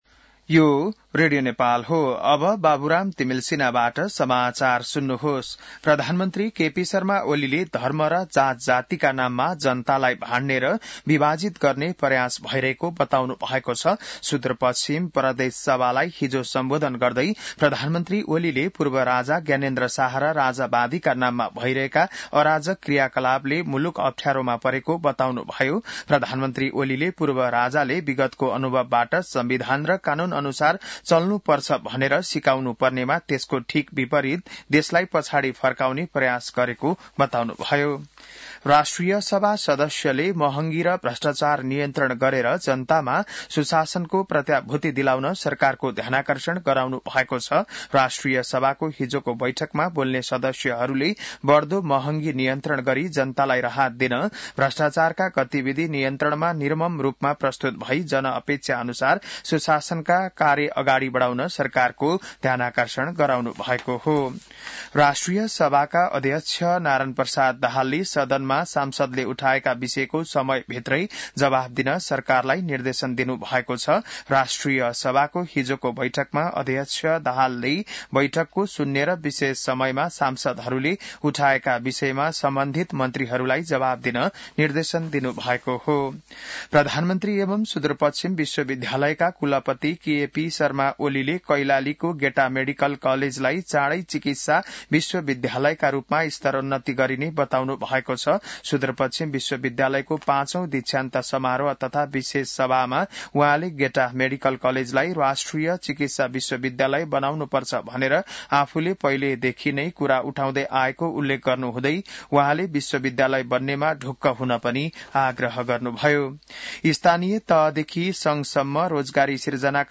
An online outlet of Nepal's national radio broadcaster
बिहान १० बजेको नेपाली समाचार : २७ फागुन , २०८१